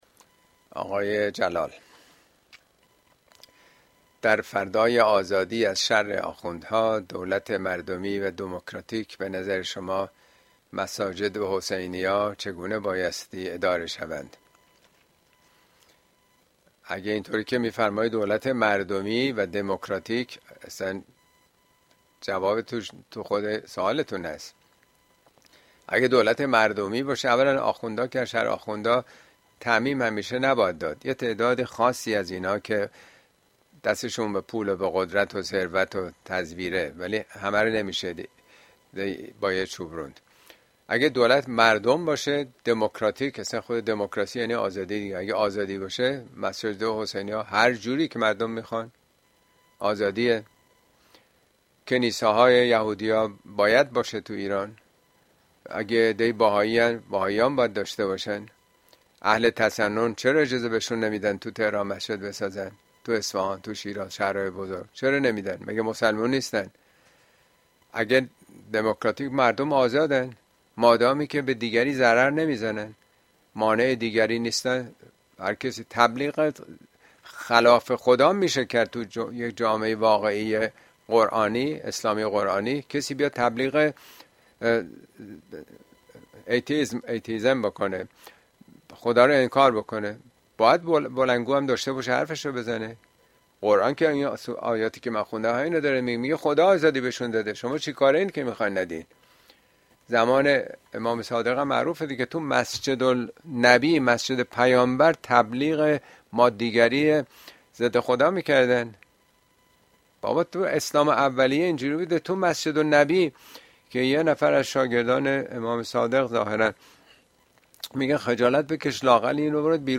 Speech
` موضوعات اجتماعى اسلامى !مسجد ضرار و آزادی مردم اين سخنرانى به تاريخ ۱۵ می ۲۰۲۴ در كلاس آنلاين پخش شده است توصيه ميشود براىاستماع سخنرانى از گزينه STREAM استفاده كنيد.